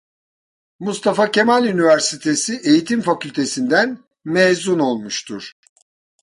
Pronúnciase como (IPA)
/meːˈzʊn/